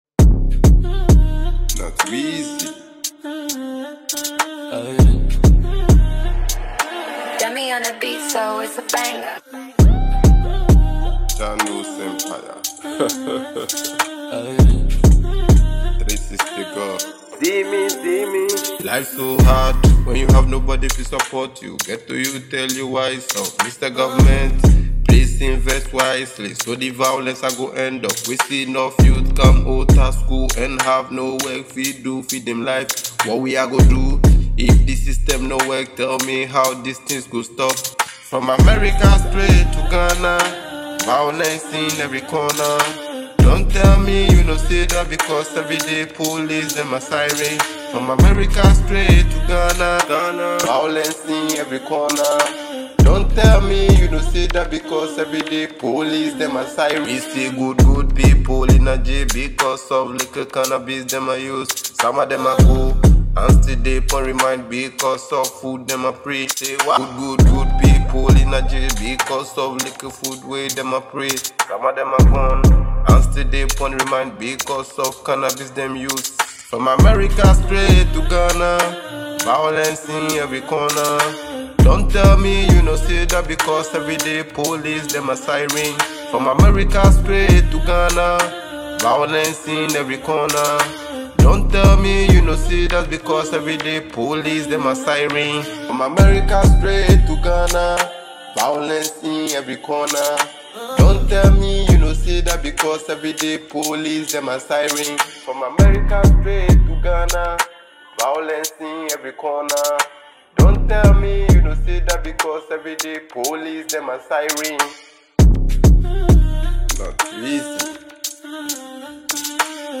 Genre: Hip-Hop